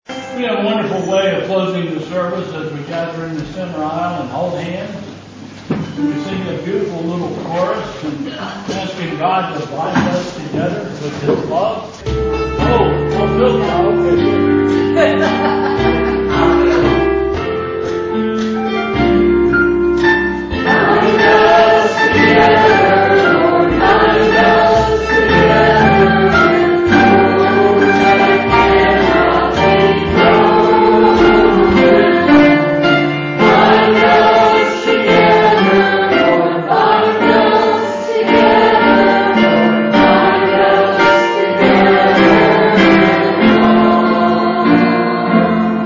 Bethel Church Service
Hymn: "Turn Your Eyes Upon Jesus"